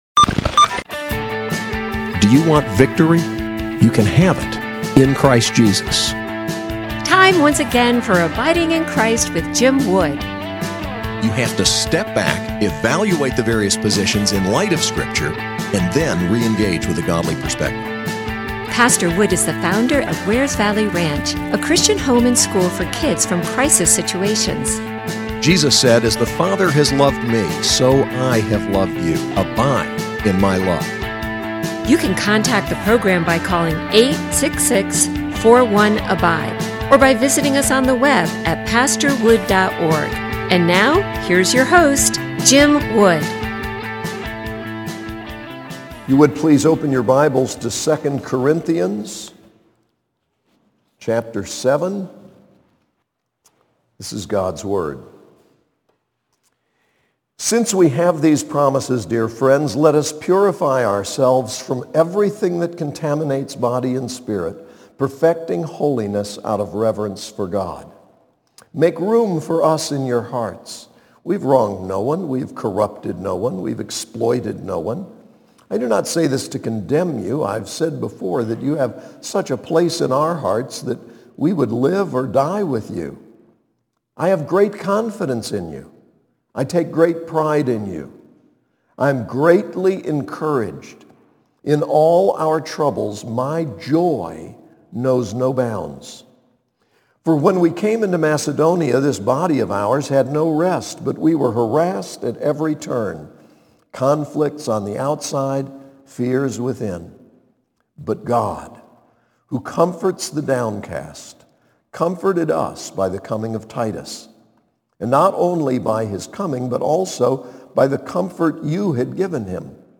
SAS Chapel: 2 Corinthians 7